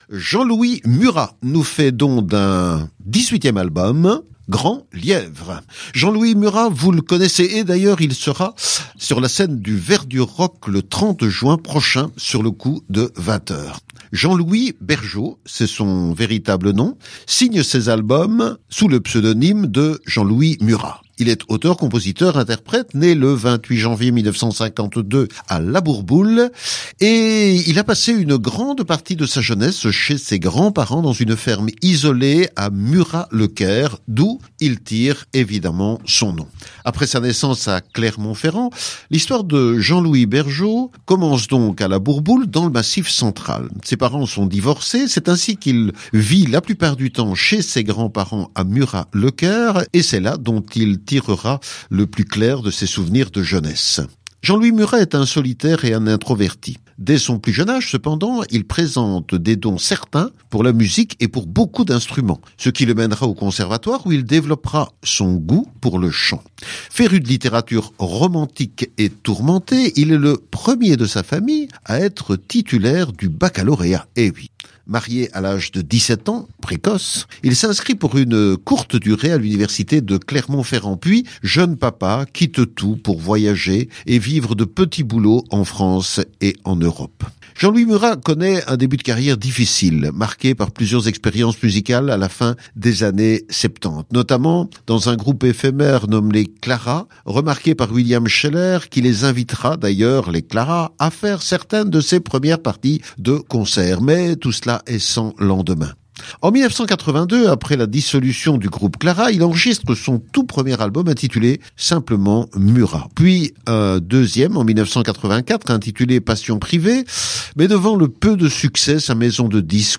Enregistré en quelques jours dans le sud de la France